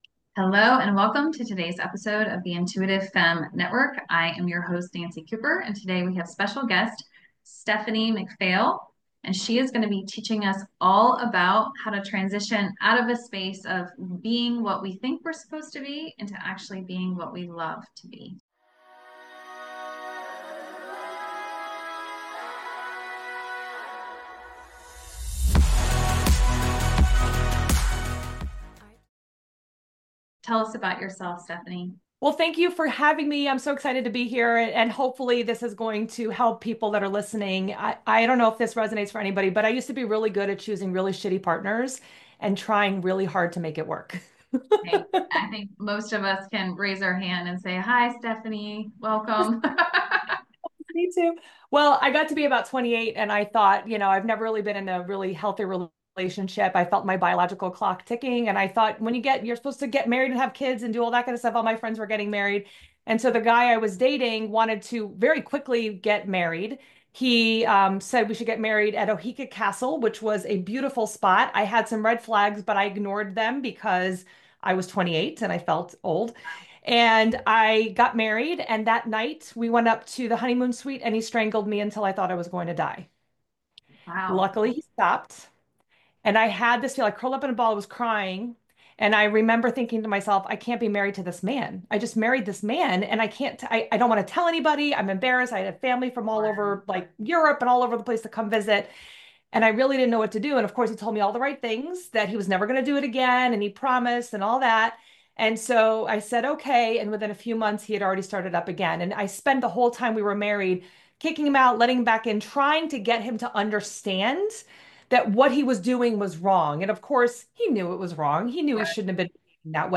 Join us for an empowering conversation that offers hope and practical advice for anyone looking to reclaim their life and happiness.